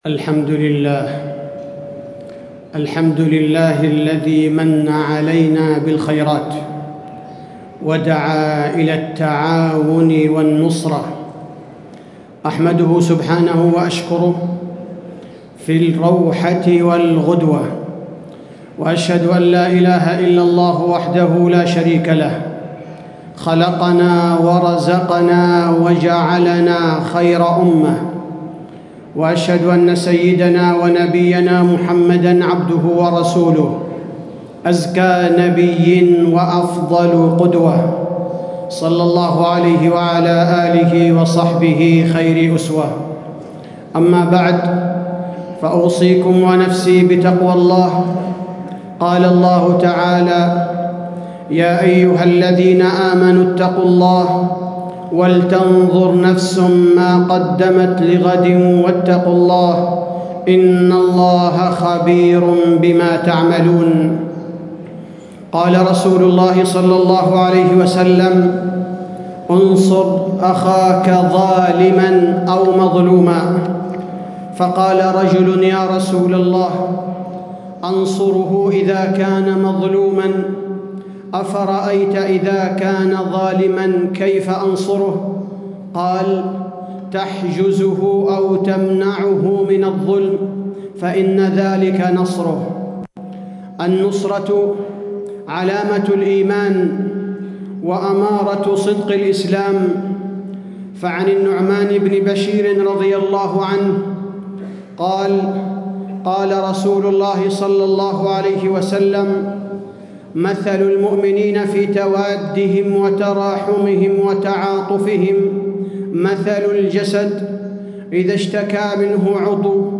تاريخ النشر ٢٦ رجب ١٤٣٦ هـ المكان: المسجد النبوي الشيخ: فضيلة الشيخ عبدالباري الثبيتي فضيلة الشيخ عبدالباري الثبيتي مظاهر نصرة المسلمين